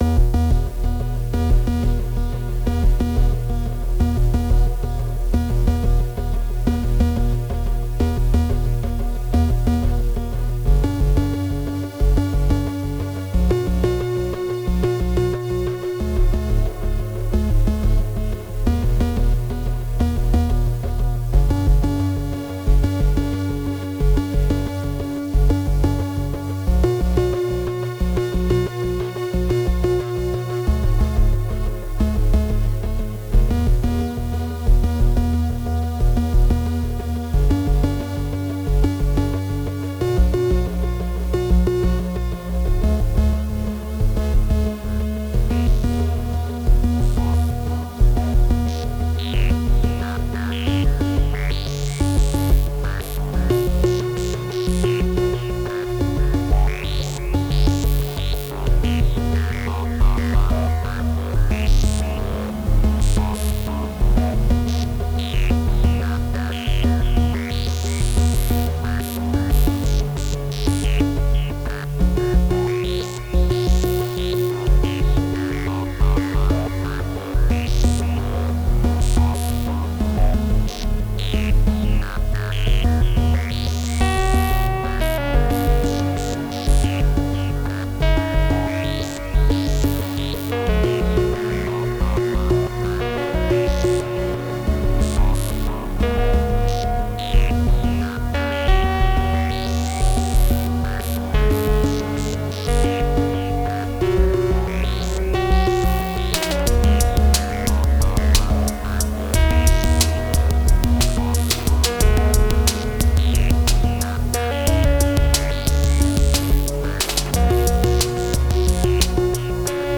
Le beatmaker français
titres alphabétiques et futuristes